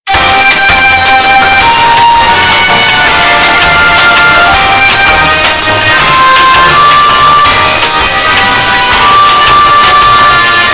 Elevator Music … but its loud
musica-elevador-short_CNEma6b.mp3